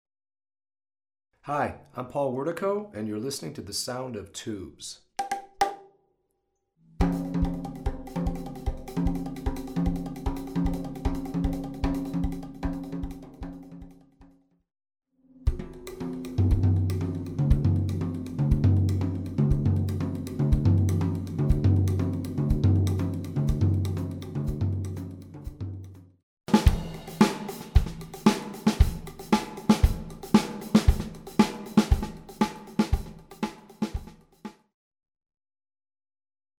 Made of durable plastic, "Tubz" produce a hollow tubular sound when played on virtually any percussion instrument.
These semi-flexible plastic tubes were designed by Pat Metheny Group drummer Paul Wertico. They produce a "hollow," high-pitched sound that resembles certain slit-drum tones. When used to strike a snare drum, tom-tom, cymbal, cowbell, conga drum, or whatever, the sound can range from exotic to interesting to downright weird.
Listen to Paul demonstrating Tubz